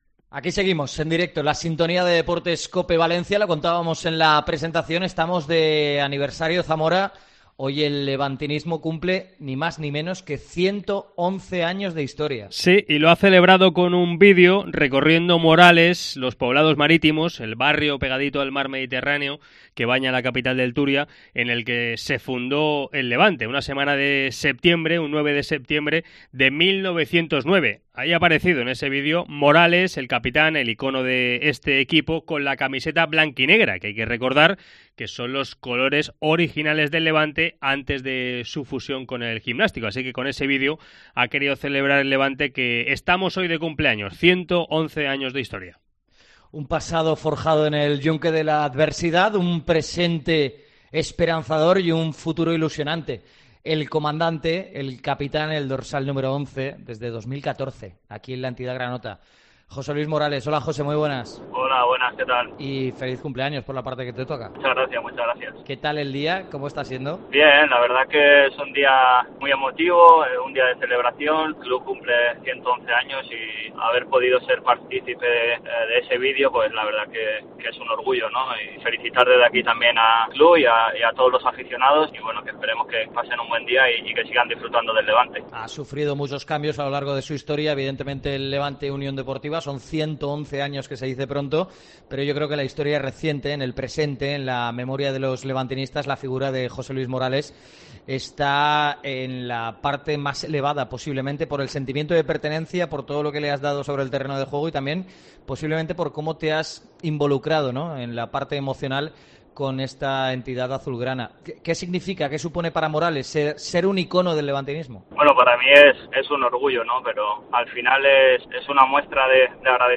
Deportes COPE Valencia ENTREVISTA